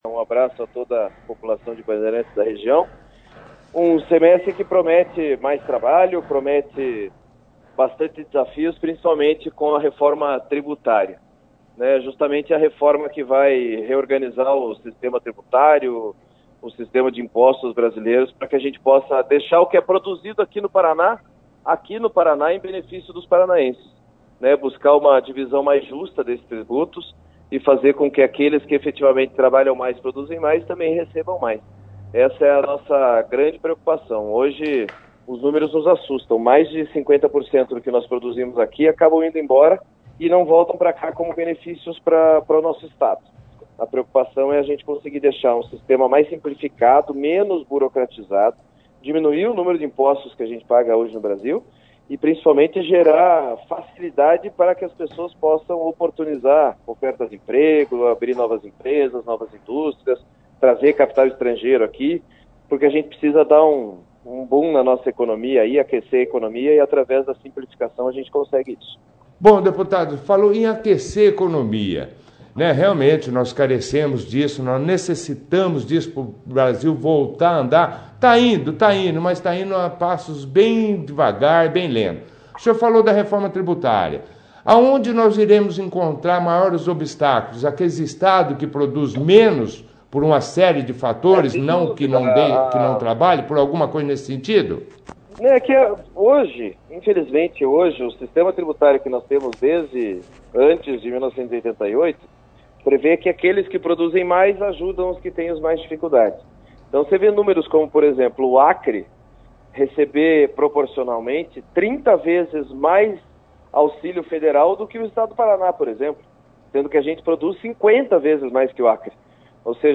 O Deputado Federal, vice-líder de Bolsonaro no Congresso, Pedro Lupion, participou da 2ª edição do Jornal Operação Cidade, desta segunda-feira, 19/08/19, falando sobre o seu trabalho na e dos desdobramentos dos projetos do governo neste segundo semestre e de recursos para a região.